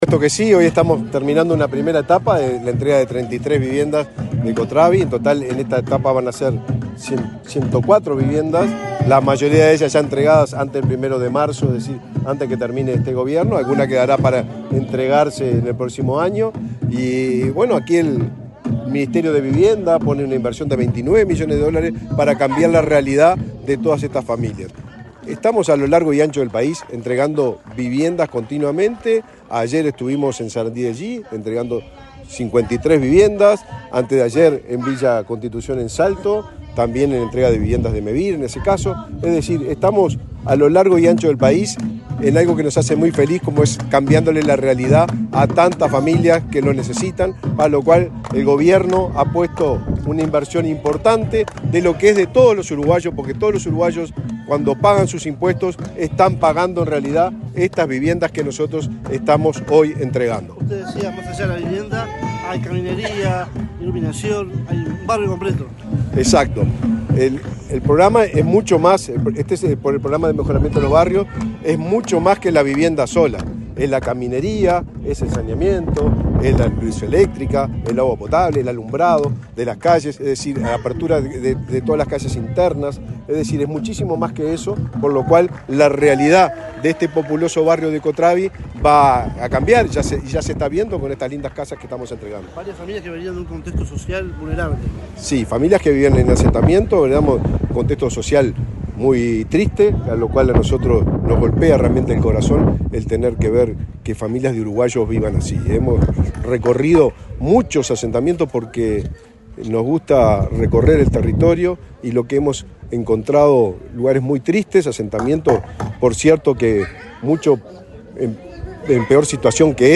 Declaraciones del ministro de Vivienda, Raúl Lozano
Luego dialogó con la prensa.